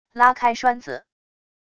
拉开栓子wav音频